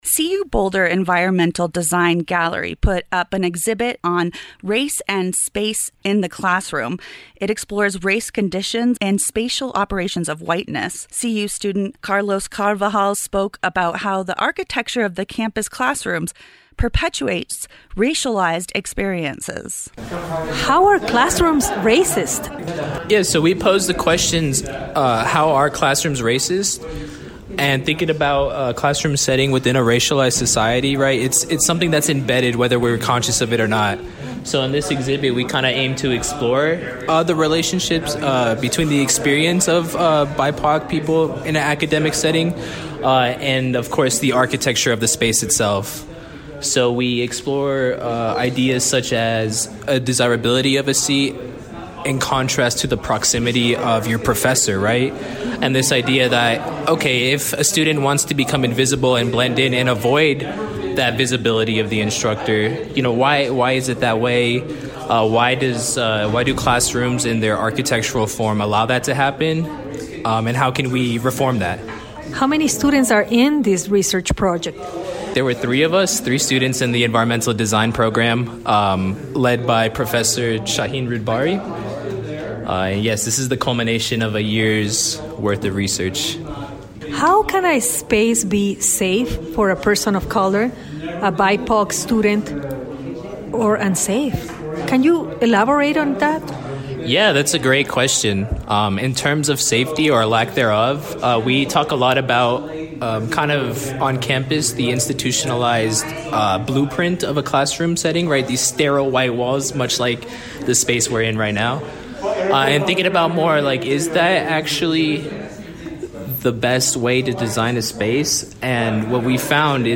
KGNU Broadcast Live On-Air